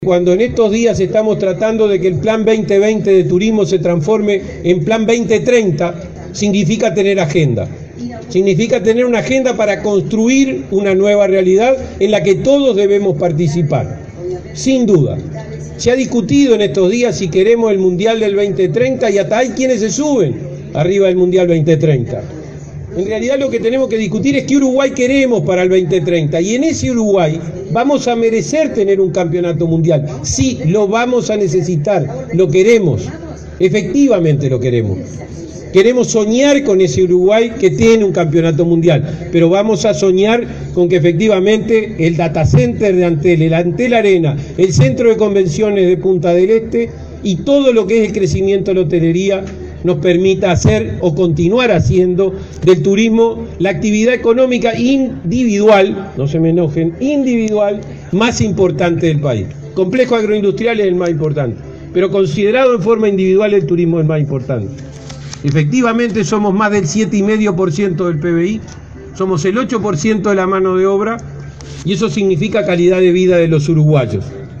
“Queremos soñar con ese Uruguay que tiene campeonato mundial de fútbol”, afirmó el subsecretario de Turismo, Benjamín Liberoff, en la apertura del estand en la Expo Prado 2017. Destacó el Data Center, el Antel Arena, el Centro de Convenciones en Punta de Este, el crecimiento hotelero como centrales para “hacer del turismo la actividad económica individual más importante”.